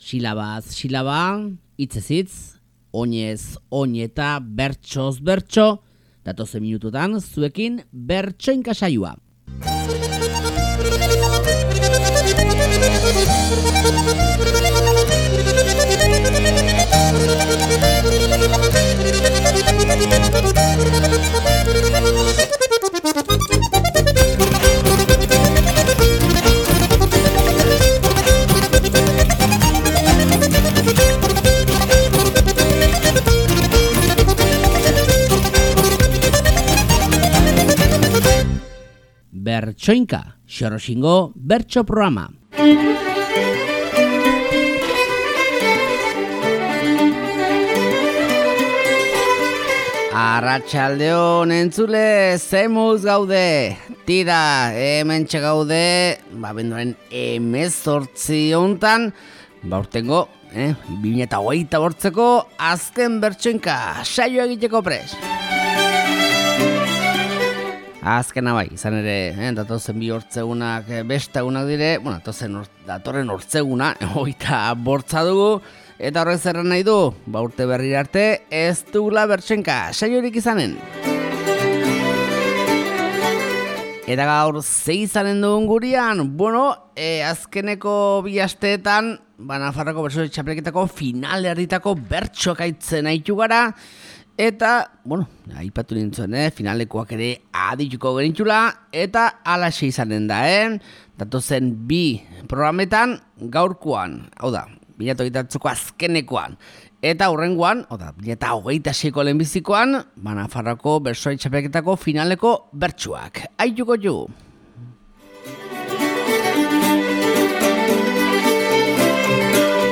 Nafarroako Bertsolari Txapelketako finaleko bertsoak urteko azken Bertsoinka saioan